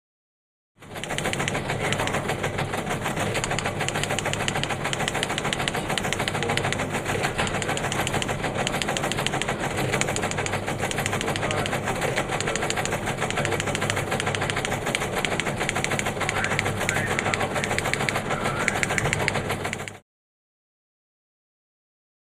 Air Traffic Control | Sneak On The Lot
Airport; Air Traffic Control; Control Tower Fornebu Airport Norway. Telex Machine Start, Run And Stop With Background Voices From Controllers And Pilots.